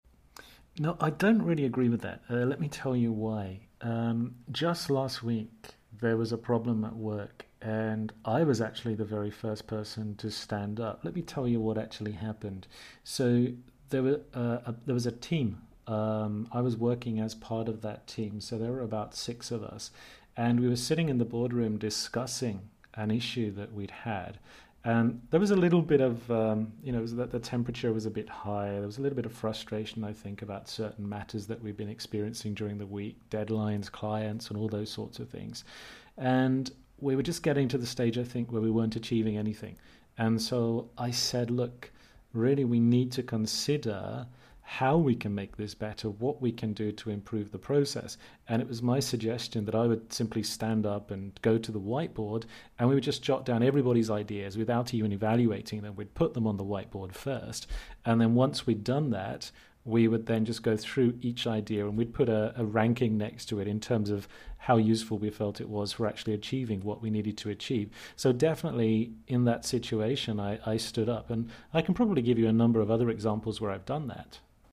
I am playing the role of the candidate.